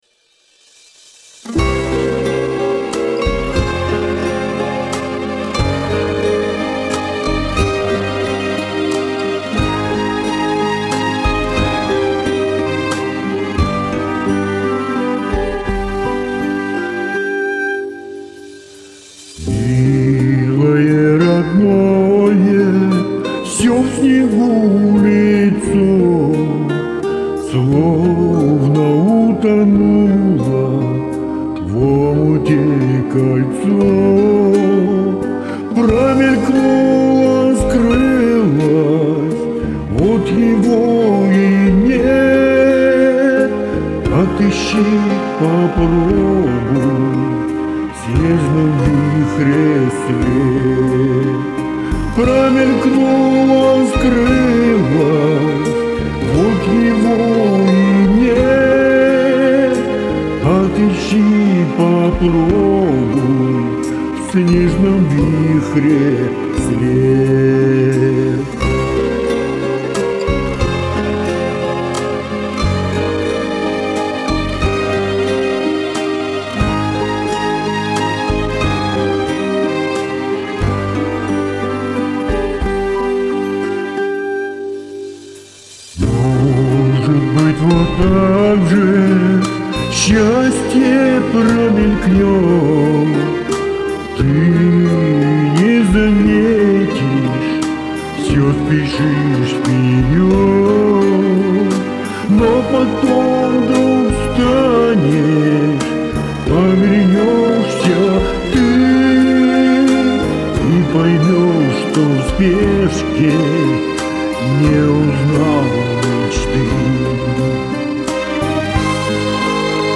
Какой красивый романс!